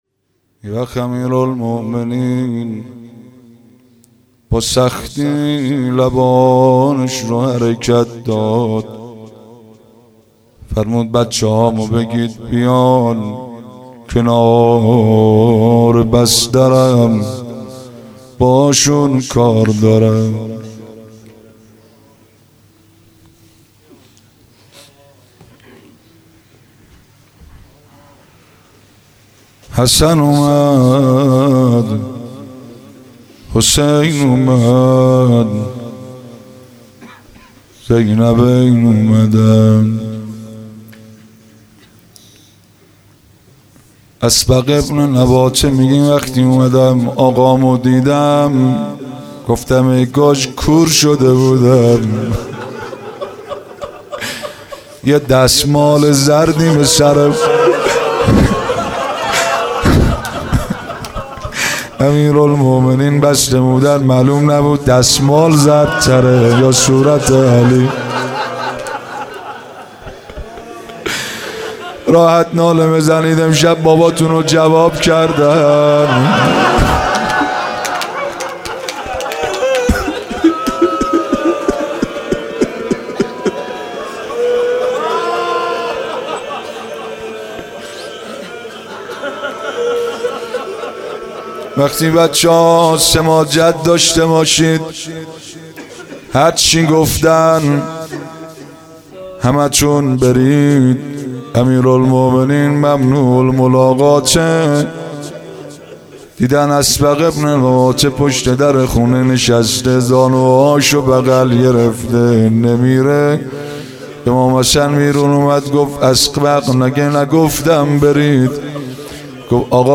روضه
روضه و ذکر